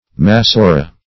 Massora \Mas*so"ra\, n.